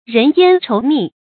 人煙稠密 注音： ㄖㄣˊ ㄧㄢ ㄔㄡˊ ㄇㄧˋ 讀音讀法： 意思解釋： 指某地方人口很多。